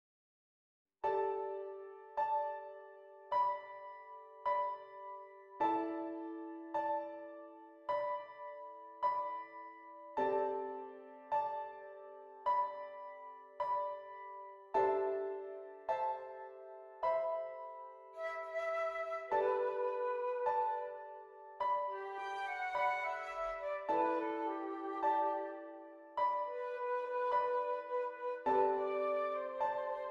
Flute Solo with Piano Accompaniment
G Major
Moderately Slow